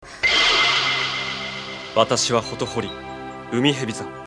Seiyuu:
Koyasu Takehito (Nihongo)
hotohori_japanese.mp3